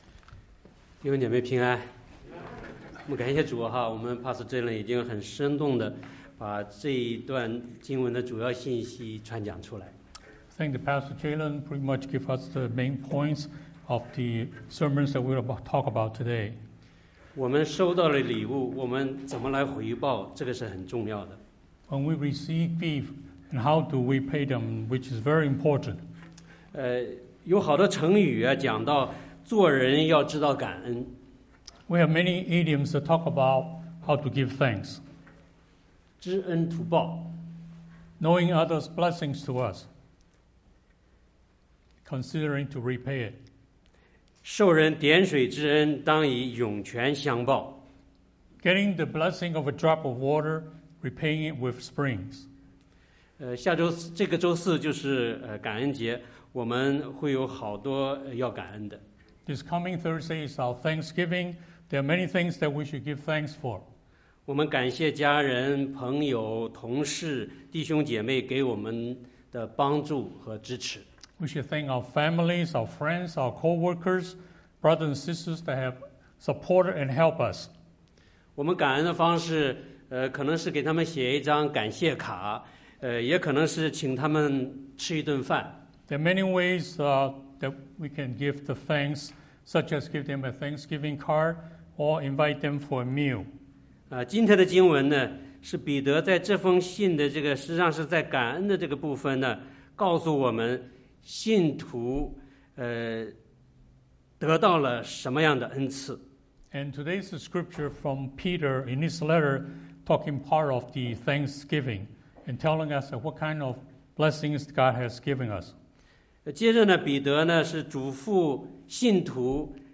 [2021感恩節聯合崇拜] 確信你的呼召和揀選
崇拜講道錄音